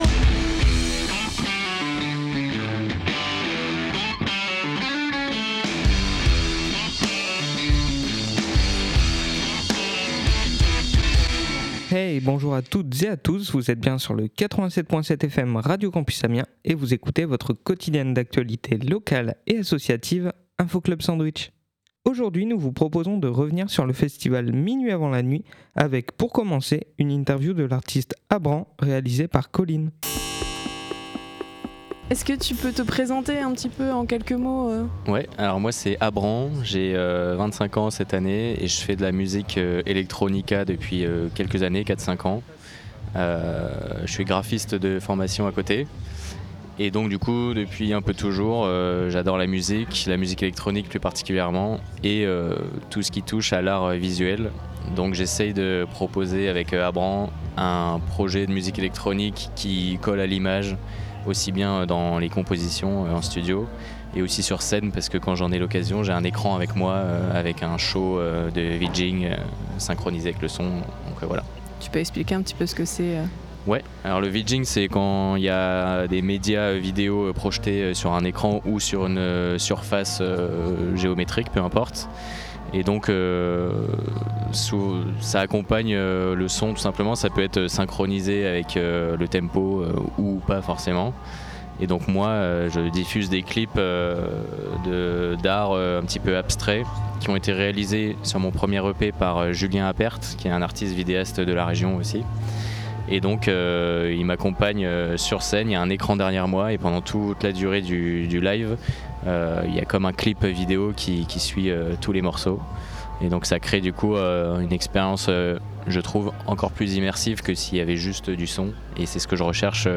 interviews
Mais également l’interview des Cuisines de Minuit.